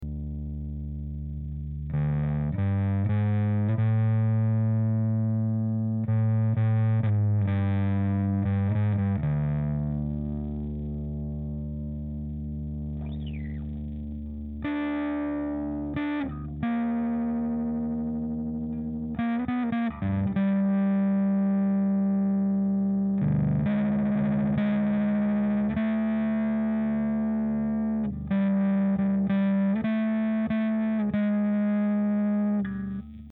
samples realisés avec un fostex MR8 mkII et un micro AKG d11 devant un 15 " , la pedale est amplifié par un ampli sono TAPCO j800.
disto basse ,et gain à 12h .
la basse est une bas de game ibanez passive , pour dire d'etre le plus neutre possible .
sample disto1
disto2.mp3